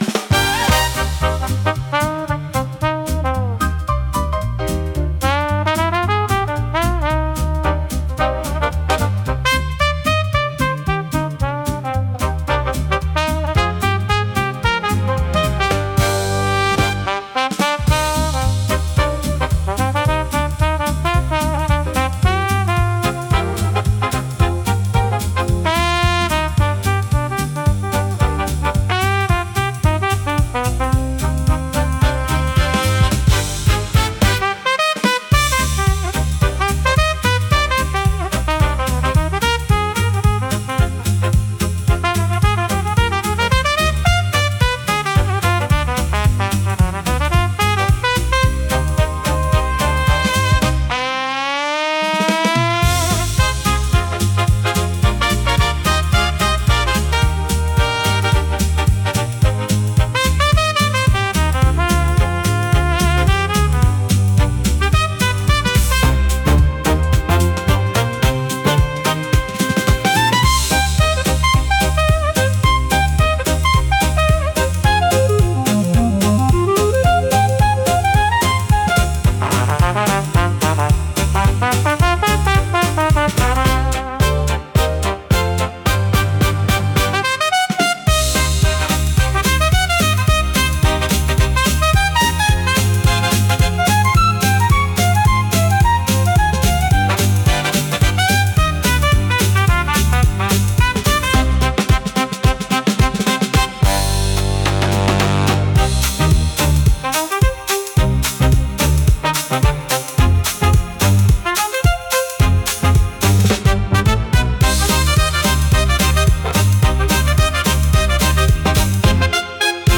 イメージ：インスト,スウィング・ジャズ,トランペット
インストゥルメンタル（instrumental）